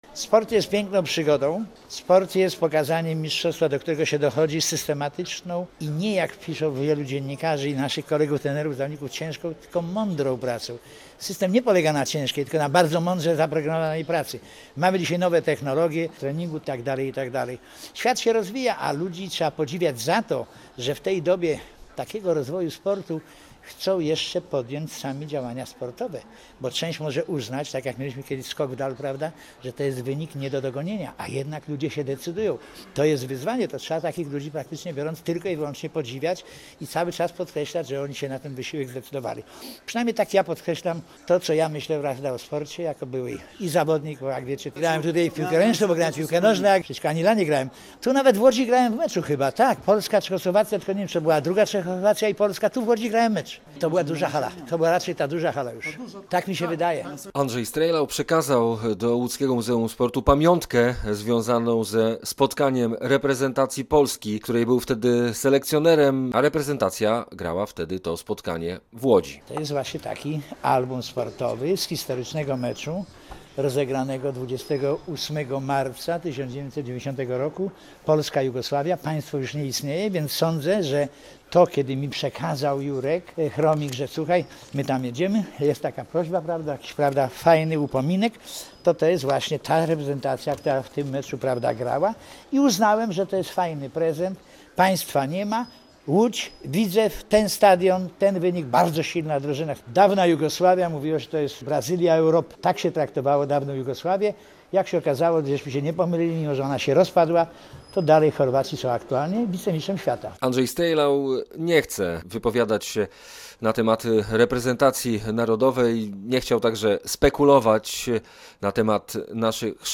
Andrzej Strejlau o sporcie i swojej dalszej karierze w rozmowie z Radiem Łódź - Radio Łódź